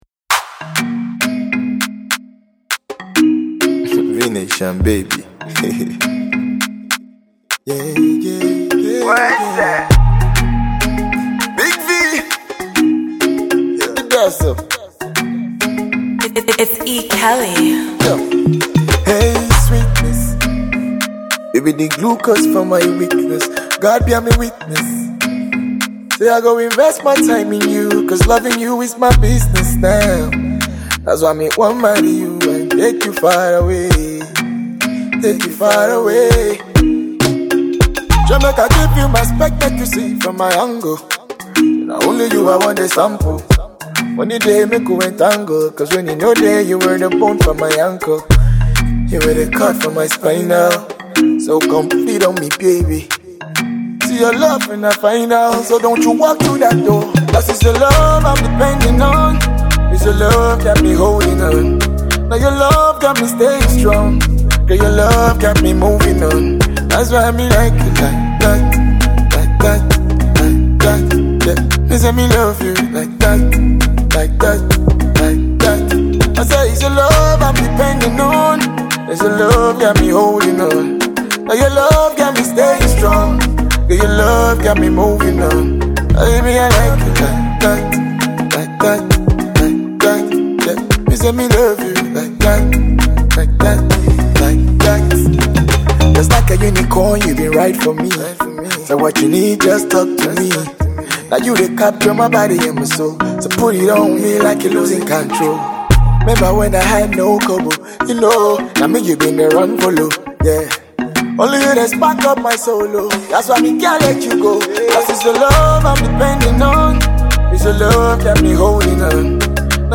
African Dancehall Champ
easy dancehall feel